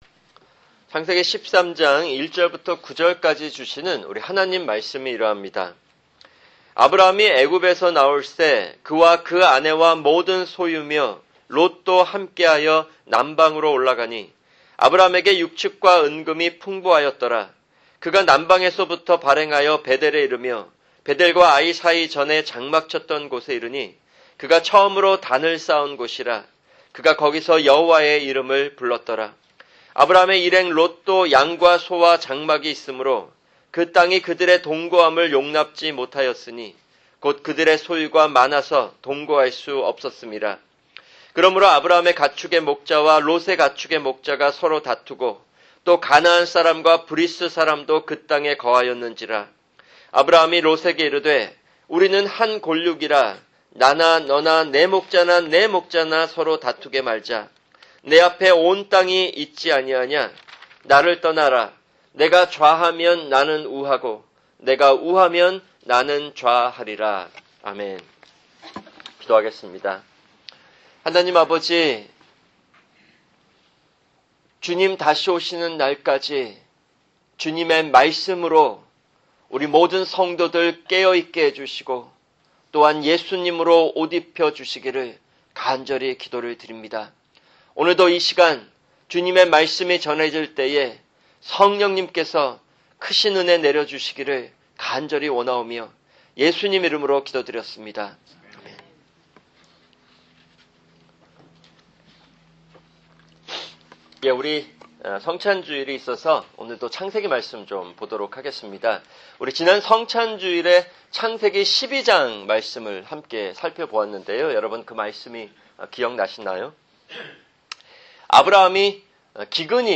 [주일 설교] 창세기13:1-18